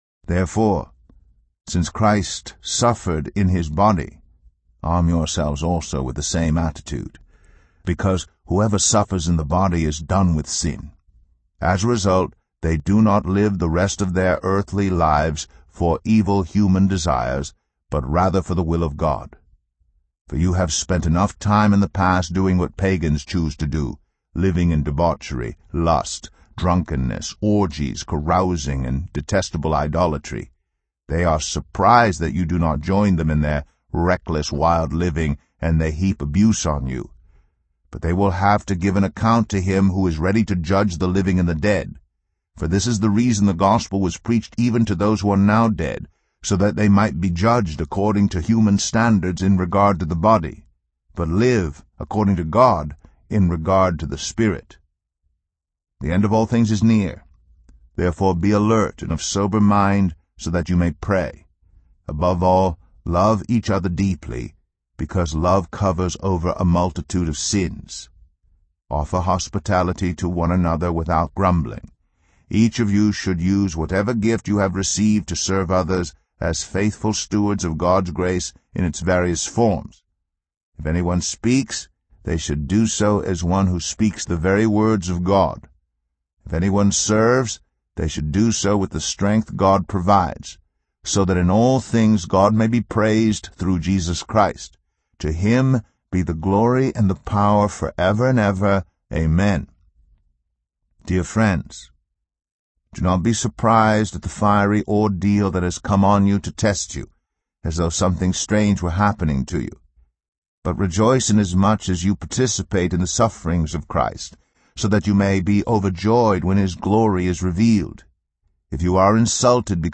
Spiritual Gifts – Bible Reading – Day 4